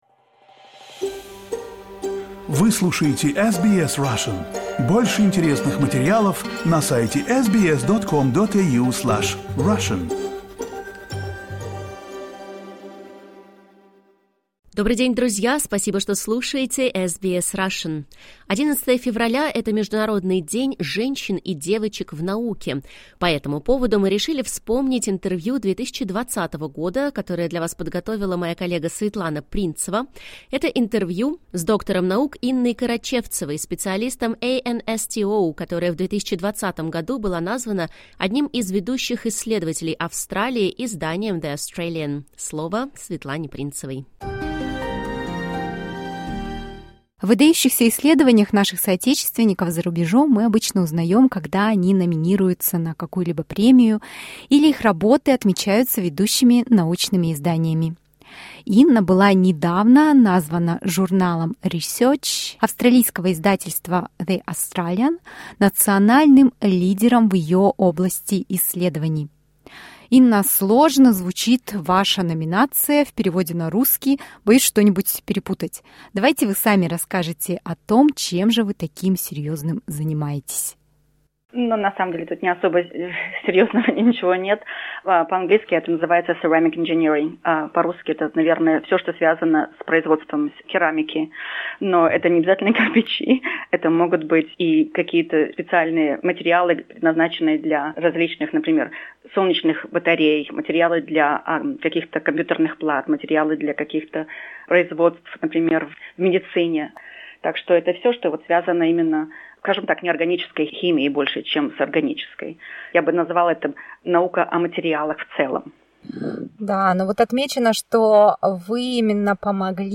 В интервью мы также поговорили о планах австралийского правительства привлекать женщин в науку, насколько они реальны.